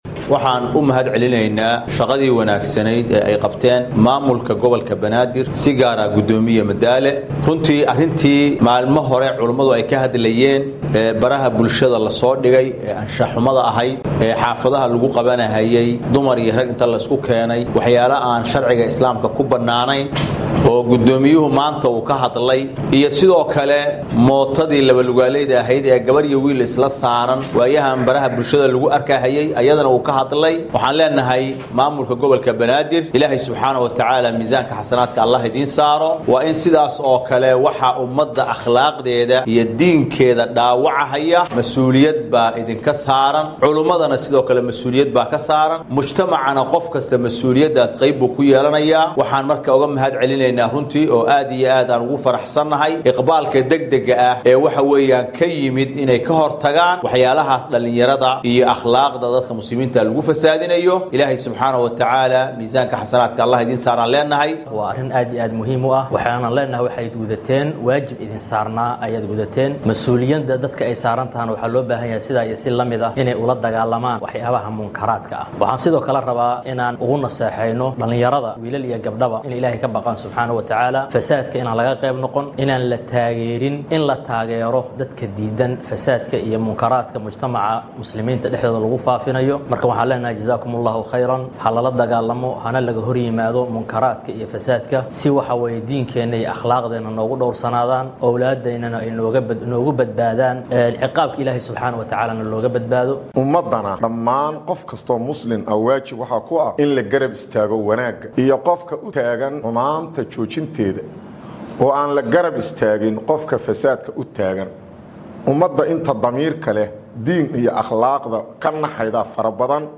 Qaar ka mid ah culimada magaalada Muqdisho ee caasimadda dalka Soomaaliya oo gaaray ismaamulka Mombasa ayaa halkaasi shir jaraa’id ku qabtay. Waxay bogaadiyeen tallaabo uu qaaday goddoomiyaha gobolka Banaadir ahna duqa magaalada Muqdisho Yuusuf Xuseen Jimcaale Madaale.